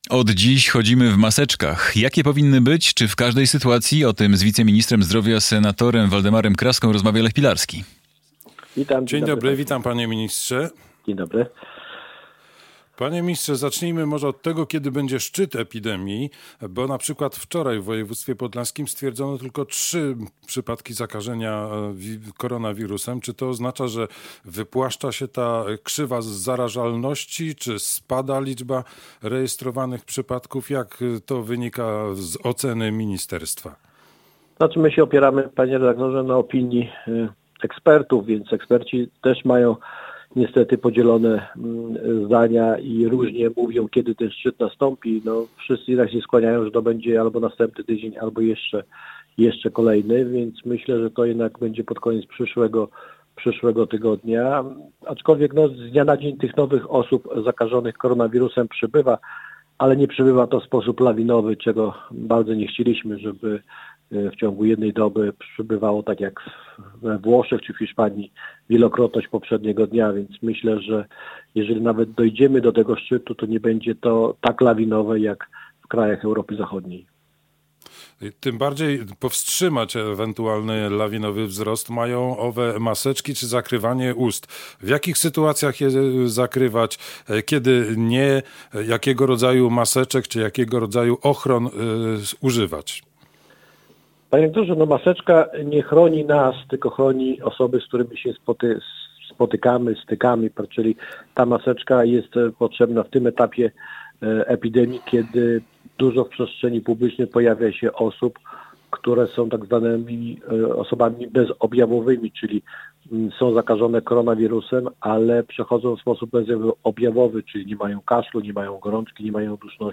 Radio Białystok | Gość | Waldemar Kraska - wiceminister zdrowia